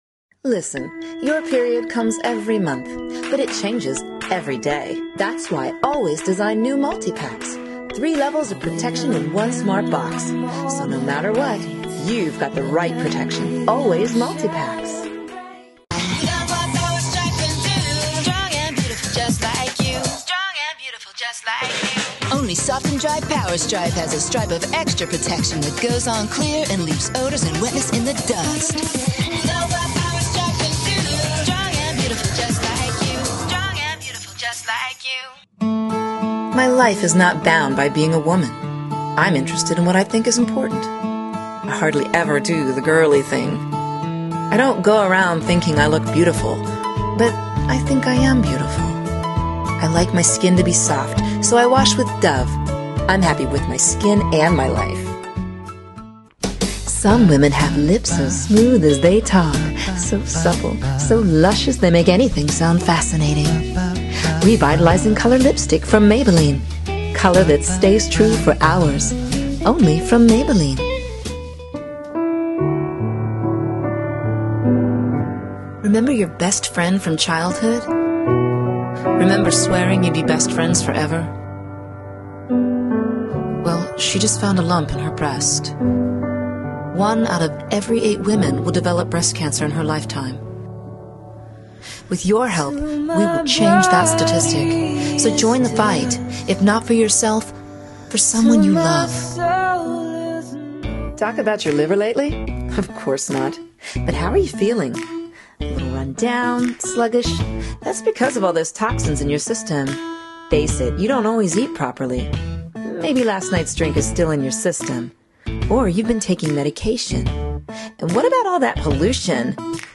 Voice Over Reel